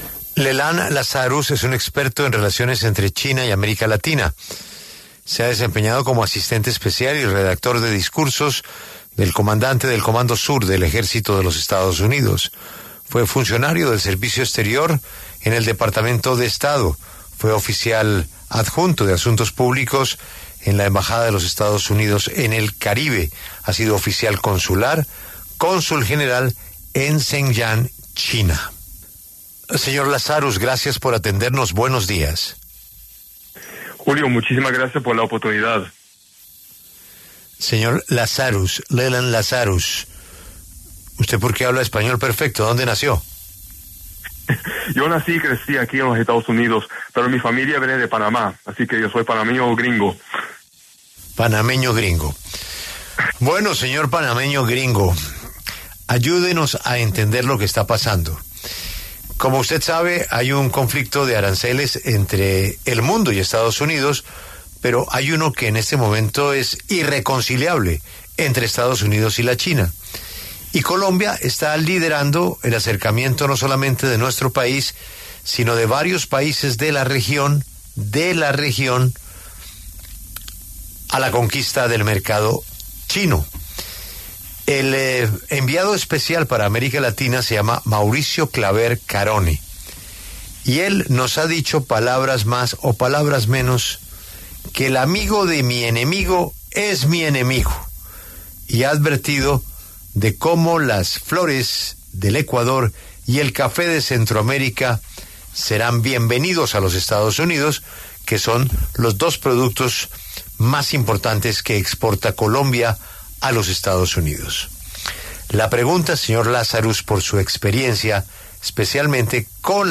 experto en las relaciones entre China y América Latina
dialogó con La W a propósito del acercamiento de Colombia con el ‘gigante asiático’.